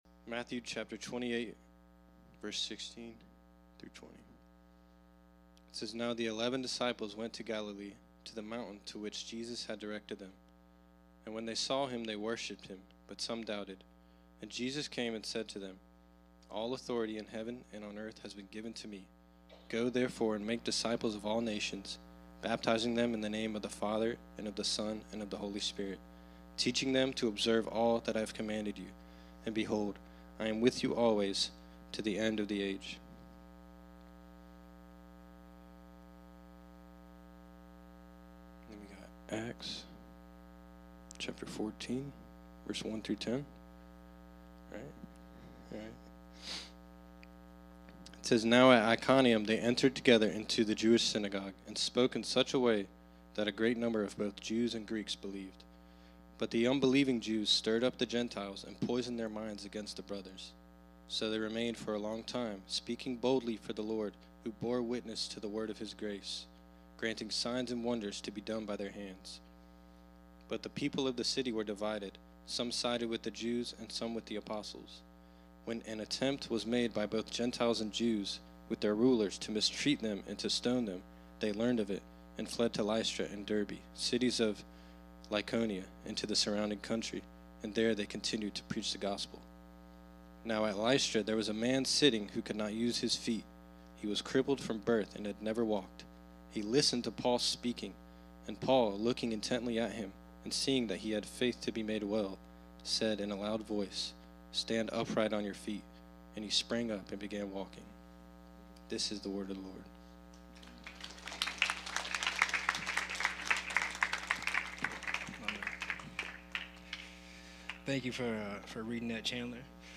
Service Type: Sunday 10am